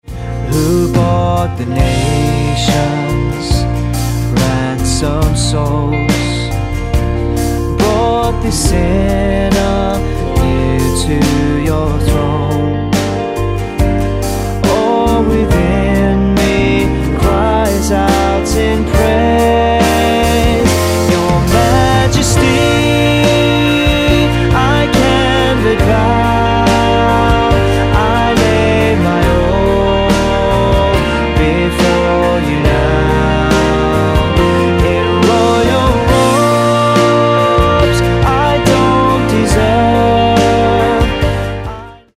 Bb